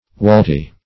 Search Result for " walty" : The Collaborative International Dictionary of English v.0.48: Walty \Wal"ty\, a. [Cf. Walter to roll.]
walty.mp3